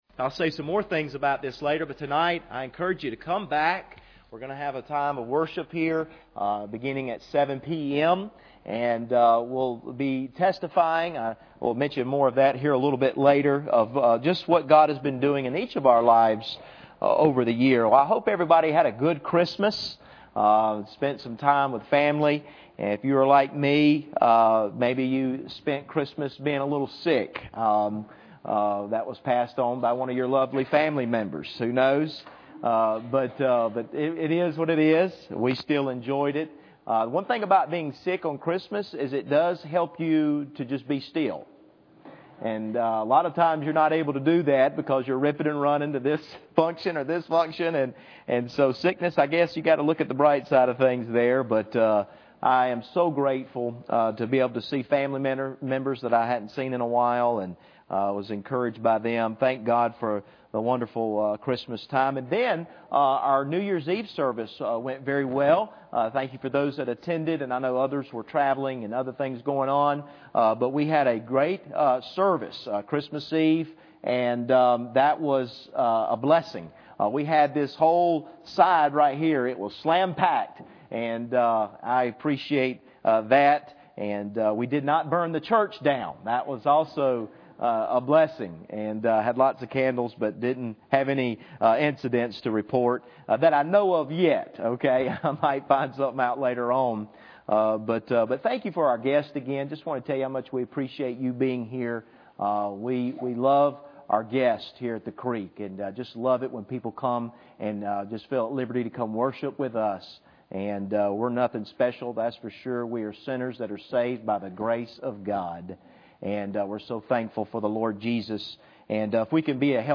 Luke 4:1-4 Service Type: Sunday Morning Bible Text